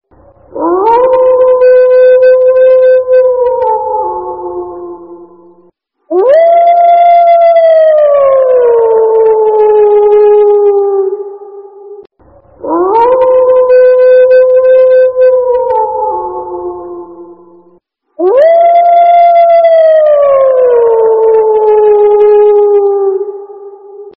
Suara Serigala Seram di Tengah Malam Bulan Purnama
Kategori: Suara horor/ buas
🌕✨ Suara serigala seram di malam bulan purnama ini pas banget buat kamu! Dengan auman khas yang mistis, suara ini cocok digunakan untuk video horor, proyek kreatif, atau sekadar jadi nada notifikasi yang beda dari biasanya.
suara-serigala-seram-di-tengah-malam-bulan-purnama-id-www_tiengdong_com.mp3